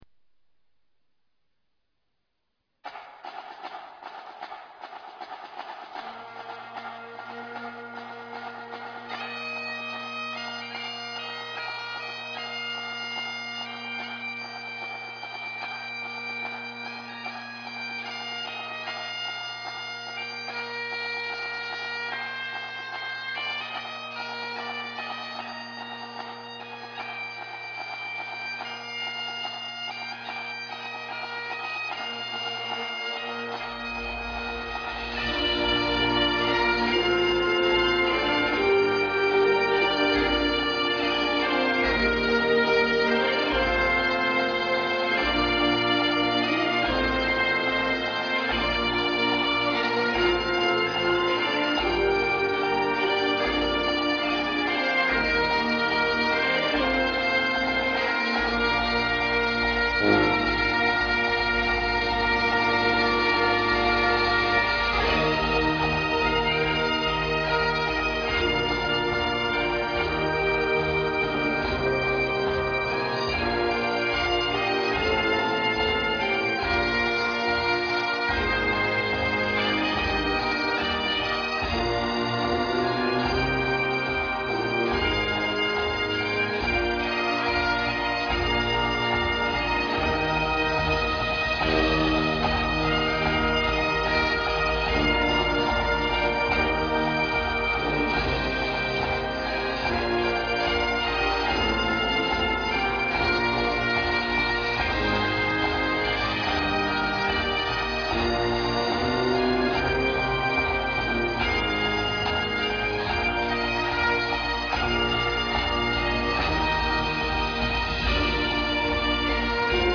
ouverture